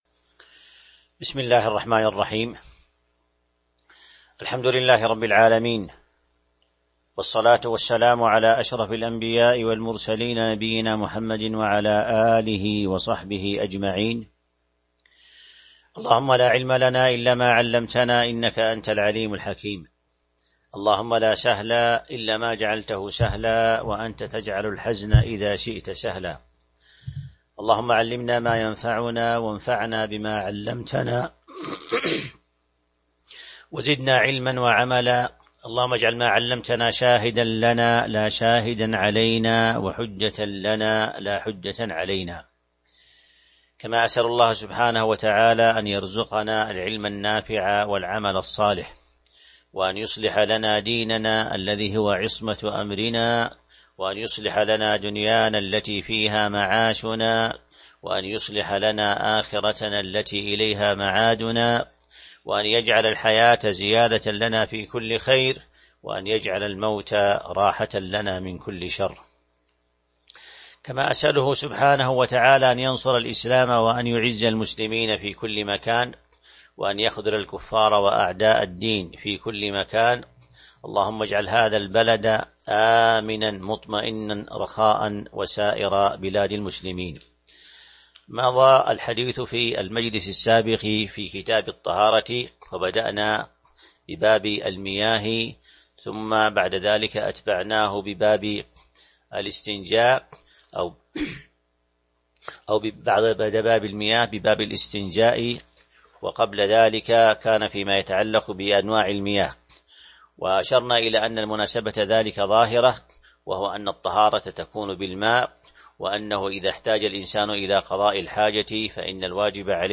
تاريخ النشر ١٥ شوال ١٤٤١ هـ المكان: المسجد النبوي الشيخ